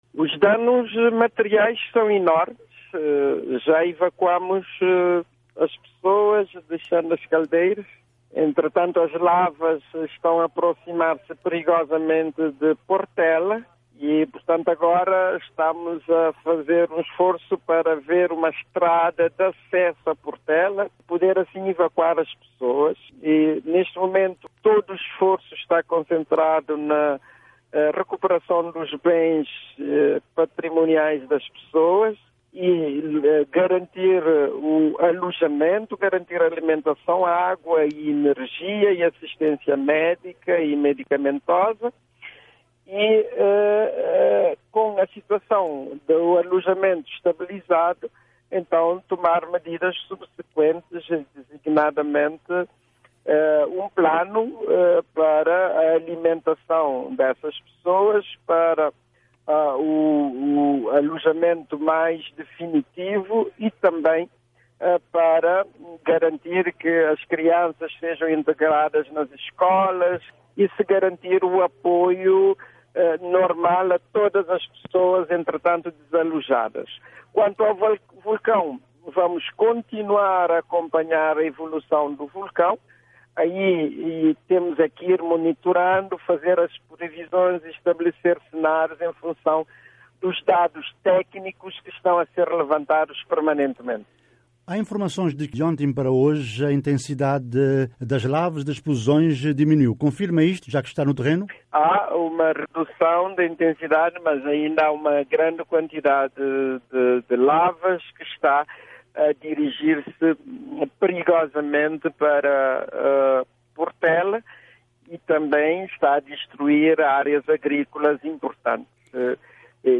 José Maria Neves falou com a VOA a partir da Chã das Caldeiras sobre a situação actual e revelou que o ministério das Relações Externas desencadeou uma série de contactos para activar a ajuda internacional.